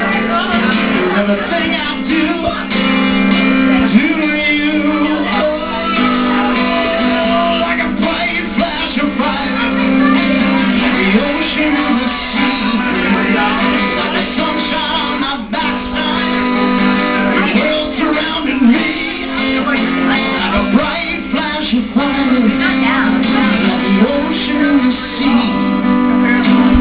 LIVE!!